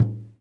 萨满教鼓（buben） " Buben3
描述：一个萨满鼓（buben）的样本，大约15年前在Kurgan镇的西西伯利亚录制。
Tag: 萨满鼓 萨满-簿本 撒满鼓 撒满-簿本